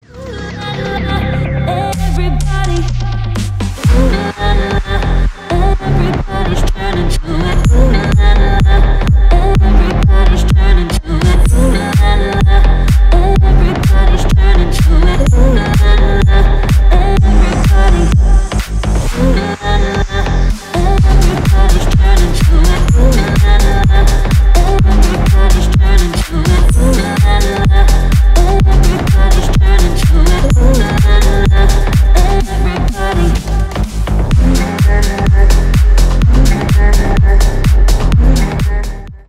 Stereo
Танцевальные
клубные